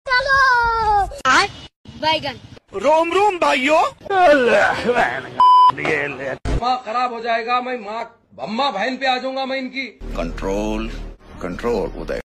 Funny memes sounds effects part. sound effects free download